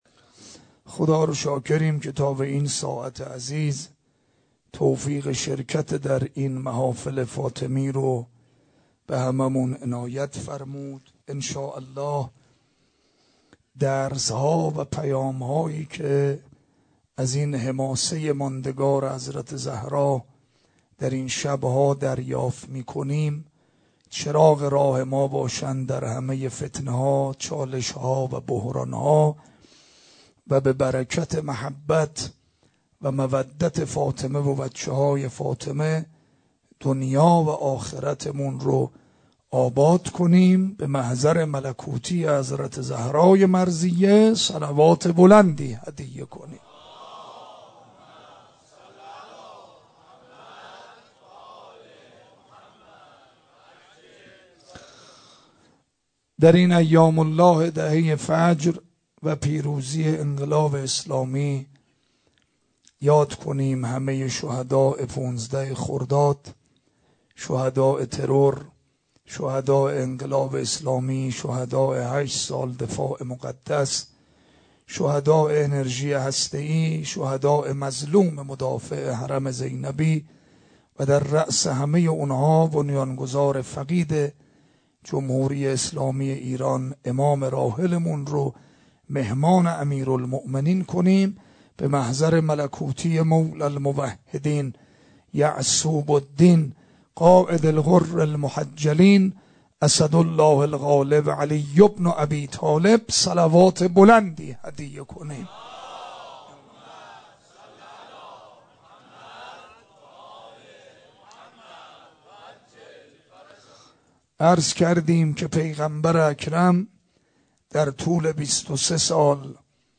سخنرانی شام غریبان فاطمیه